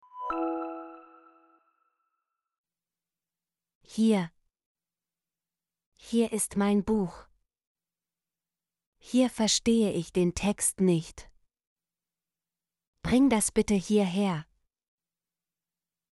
hier - Example Sentences & Pronunciation, German Frequency List